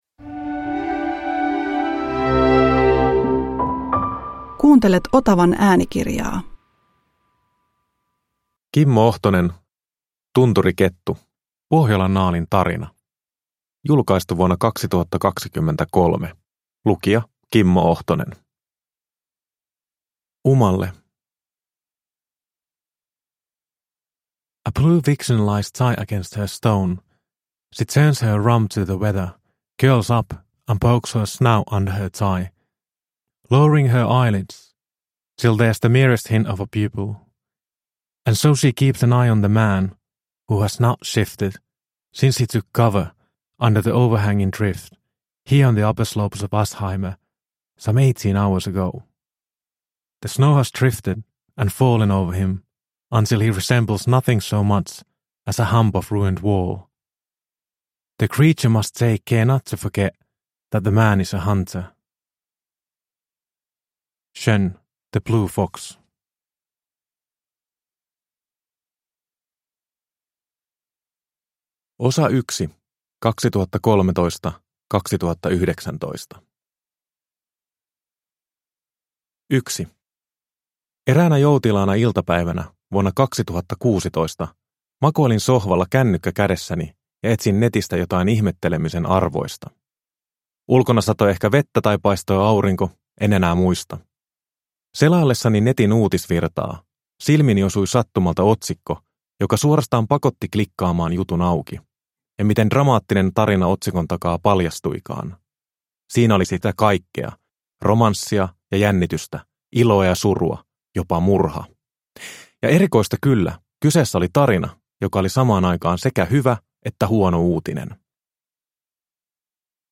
Tunturikettu – Ljudbok
Uppläsare: Kimmo Ohtonen